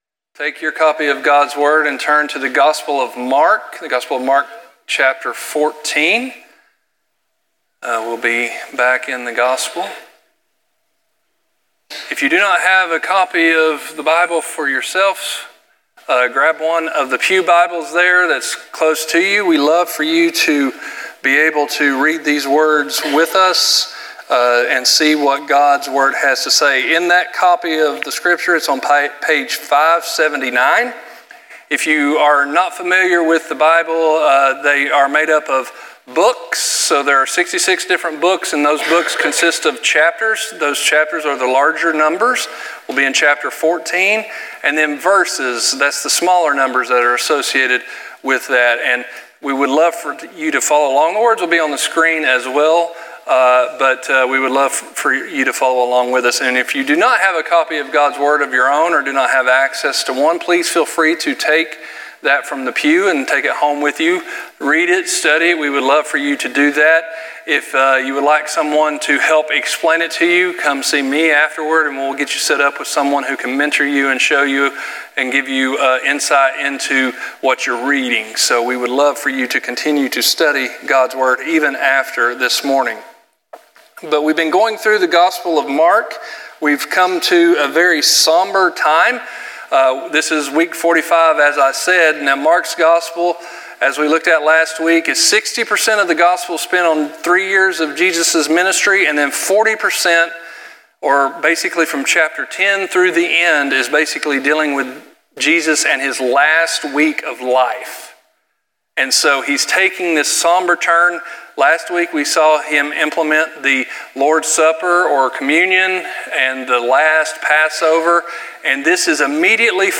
sermon.cfm